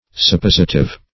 Meaning of suppositive. suppositive synonyms, pronunciation, spelling and more from Free Dictionary.
Search Result for " suppositive" : The Collaborative International Dictionary of English v.0.48: Suppositive \Sup*pos"i*tive\, a. [Cf. F. suppositif.]